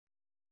♪ ormoṛa